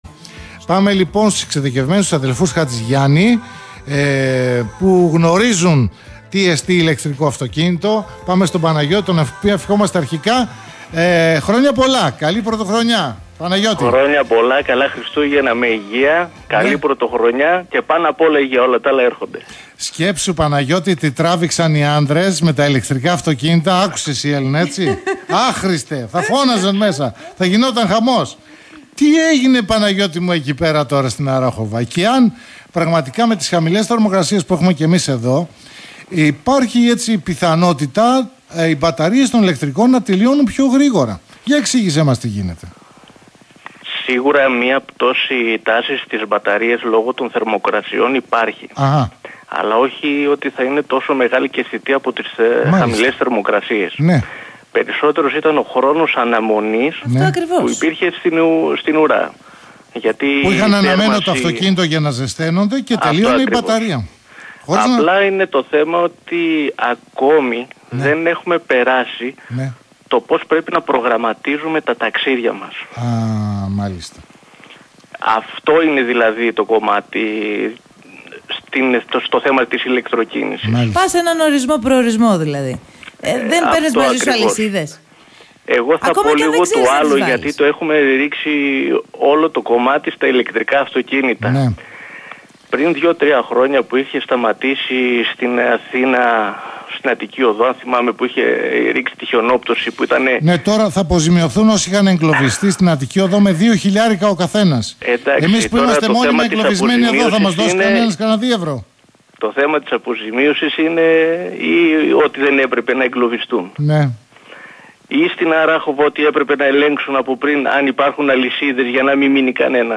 (συνέντευξη)